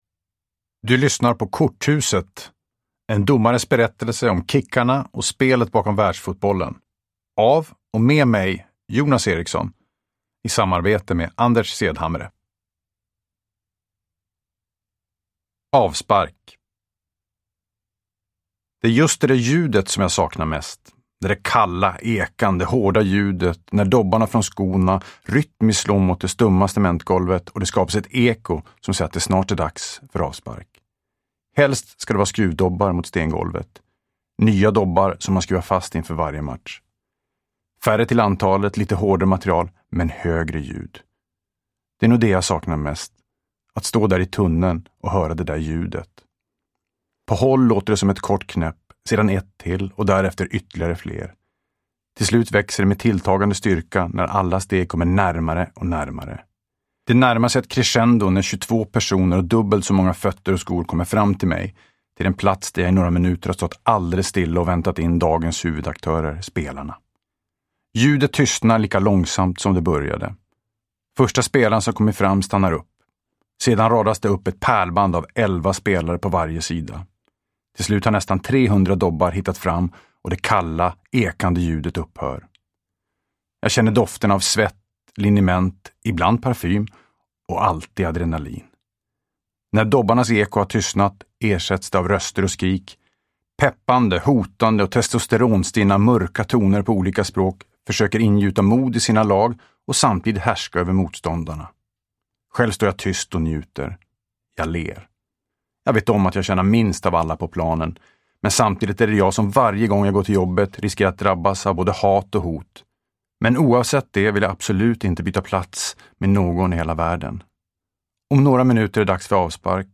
Uppläsare: Jonas Eriksson